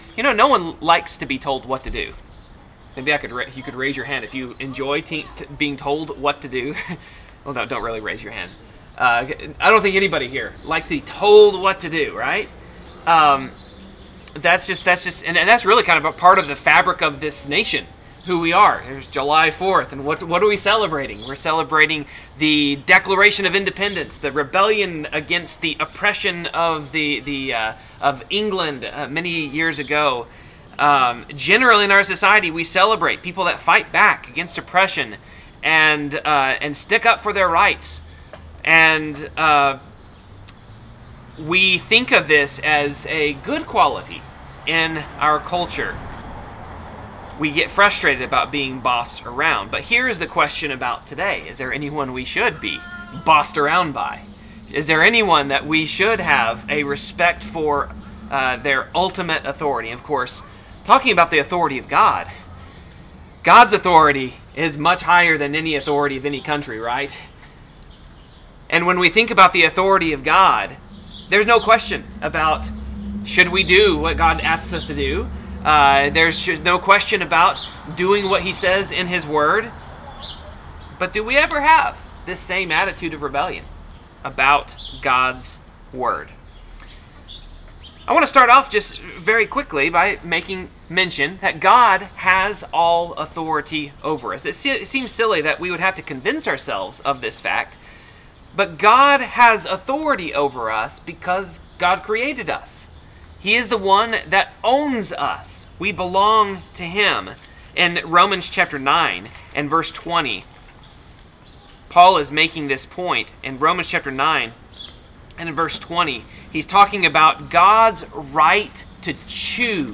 Trusting the Bible Service Type: Sermon No one likes being told what to do.